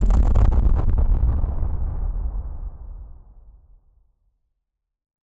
BF_DrumBombB-07.wav